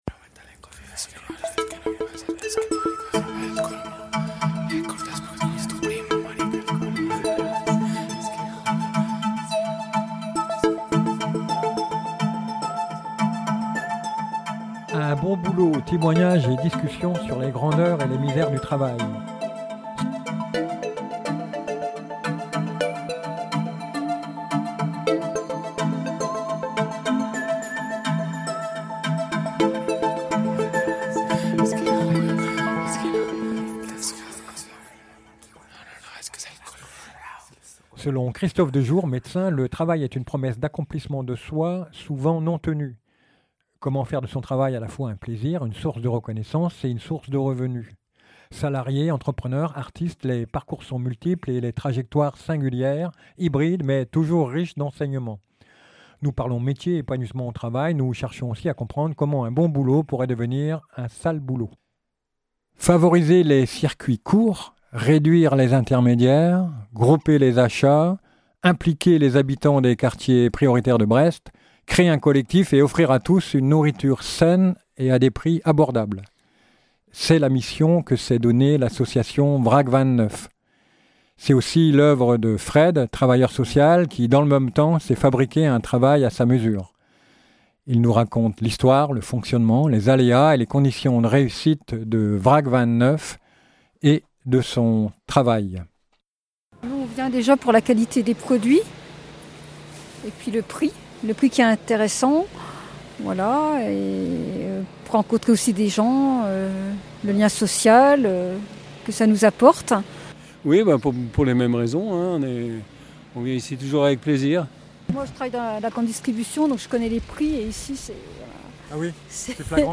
Travailleur social